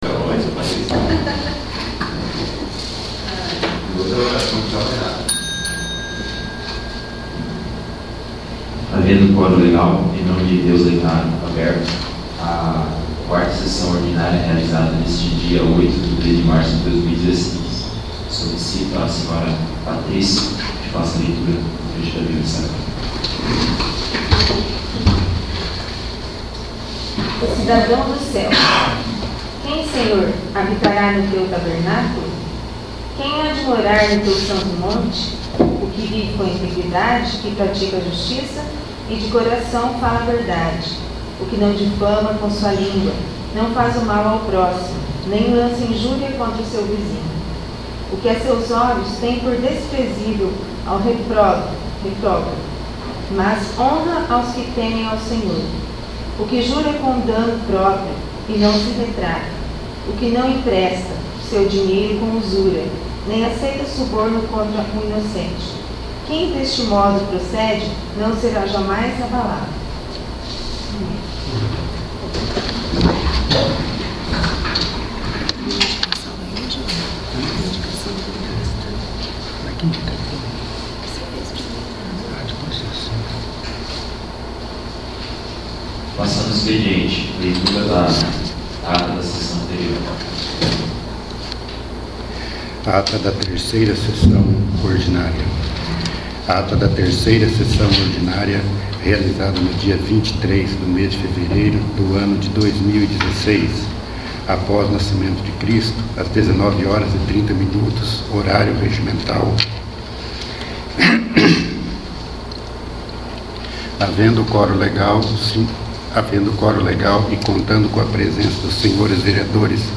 4º. Sessão Ordinária
| Ir para a navegação Ferramentas Pessoais Poder Legislativo Câmara de Vereadores do Município de Rio Bom - PR Mapa do Site Acessibilidade Contato VLibras Contraste Acessar Busca Busca Avançada…